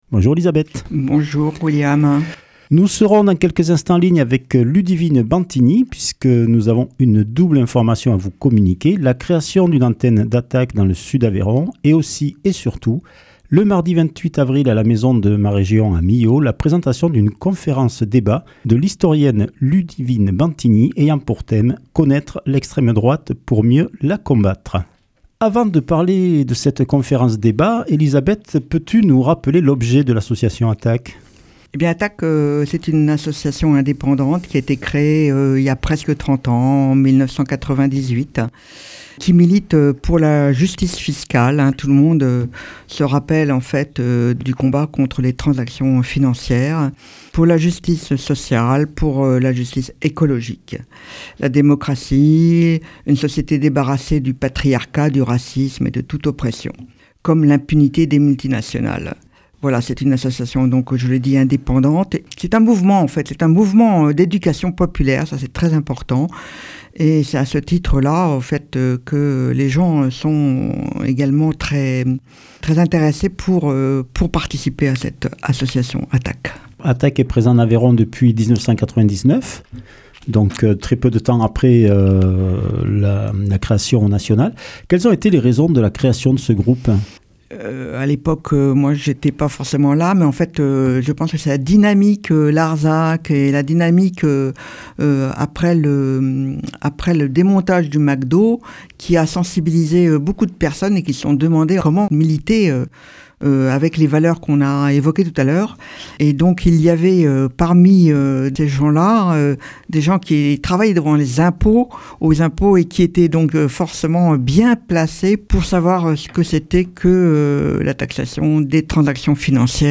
Entretien avec Ludivine Bantigny – 21 avril 2026